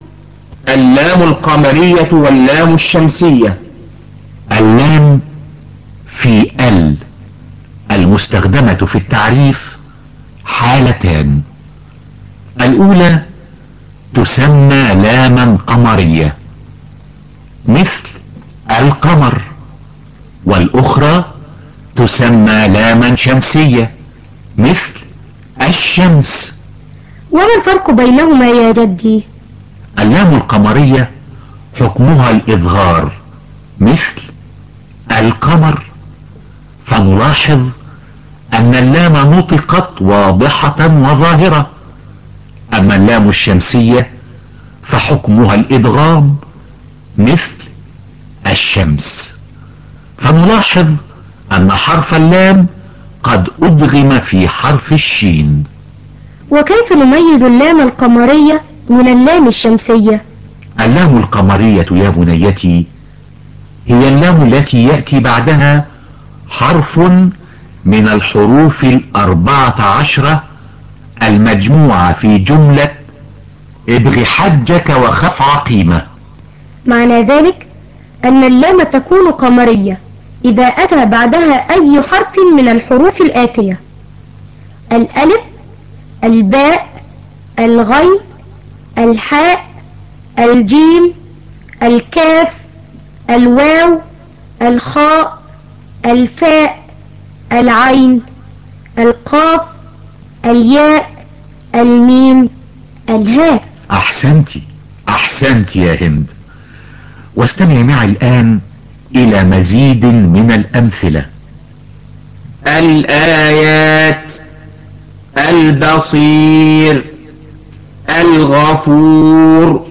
إدغام لام التعريف في الحرف الذي يليها و النطق بهما حرفاً واحداً مشدداً يكون في الأربعة عشر حرفاً الباقية ، و هي أوائل البيت الآتي :
ملاحظة : يمكن التفرقة بين اللام الشمسية و اللام القمرية بأن الحرف الذي يلي اللام الشمسية يكون دائماً مشدداً نتيجة لإدغام اللام فيه ، و الحرف الذي يلي اللام القمرية يكون مجرداً (غير مشدد) .
tajweed10.mp3